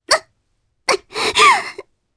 Reina-Vox_Dead_jp.wav